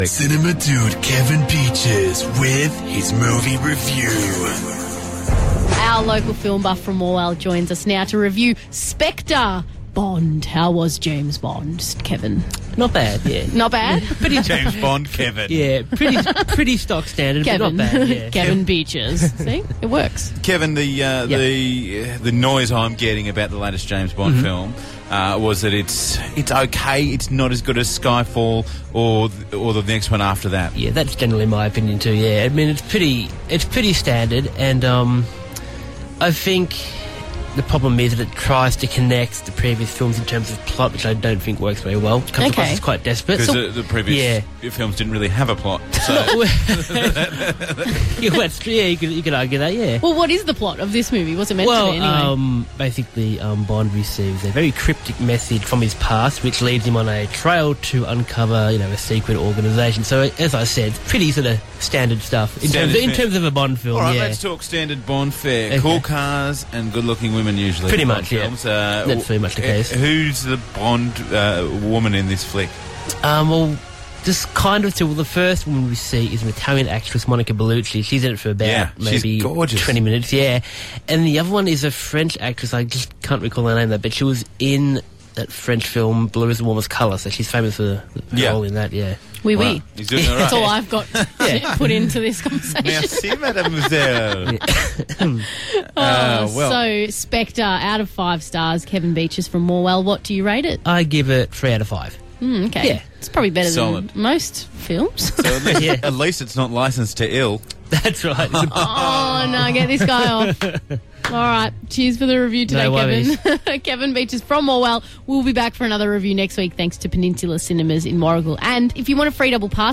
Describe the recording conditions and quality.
Reviewed on Star FM Gippsland